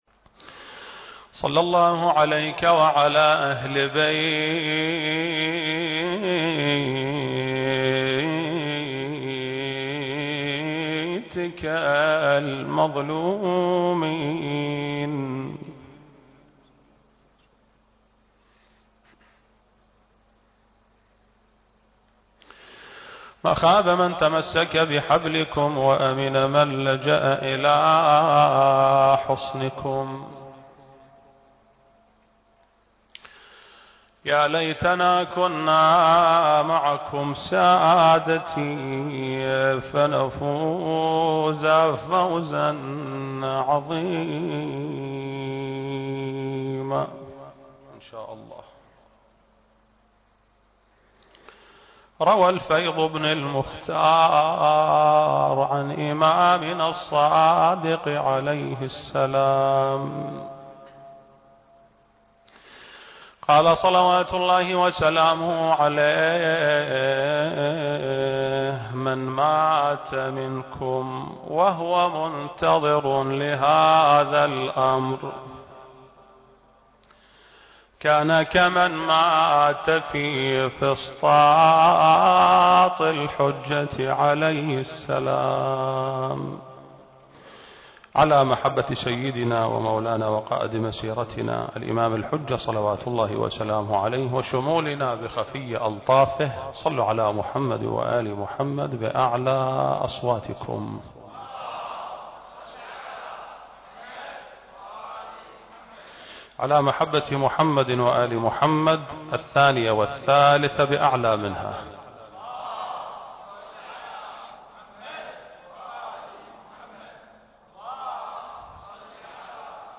المكان: مسجد آل محمد (صلّى الله عليه وآله وسلم) - البصرة التاريخ: 1442 للهجرة